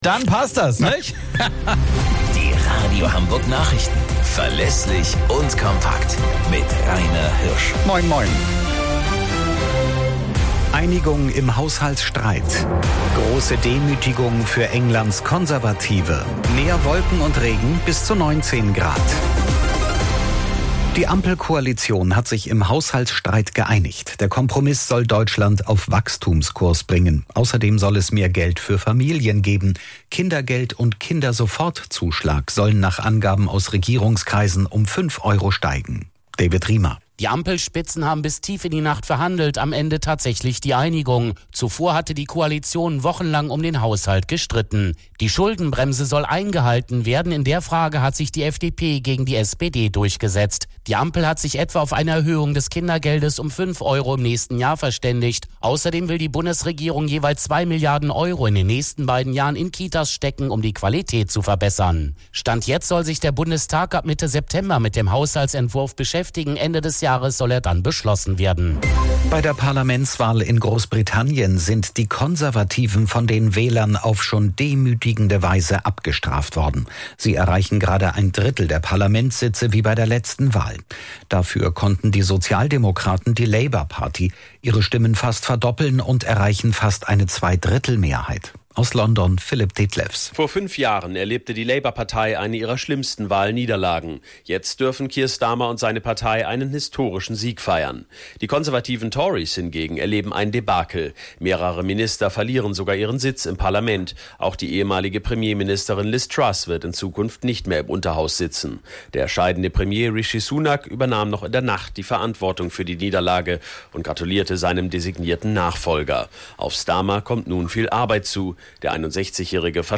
Radio Hamburg Nachrichten vom 03.08.2024 um 22 Uhr - 03.08.2024